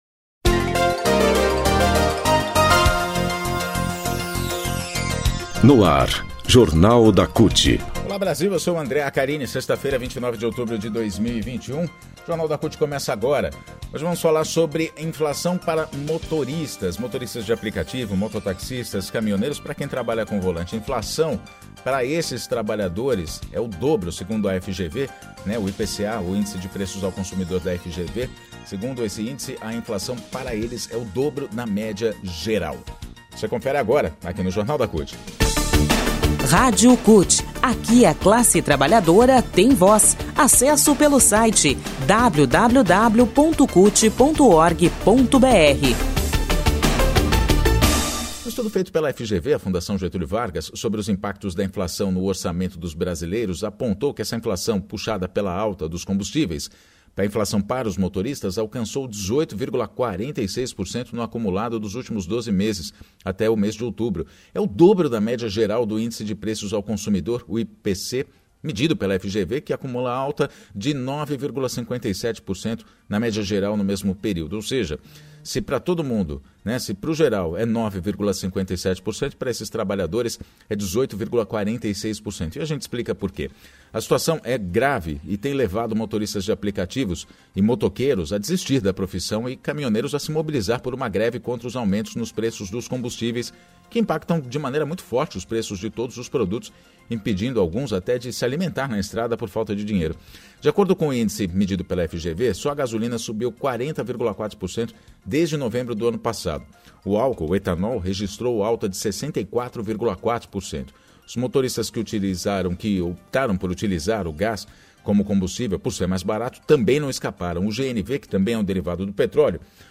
Líderes de trabalhadores autônomos falam sobre os impactos dos aumentos dos combustíveis e das dificuldades em se manter na atividade - cuja remuneração já é insuficiente – frente ao encarecimento do custo de vida.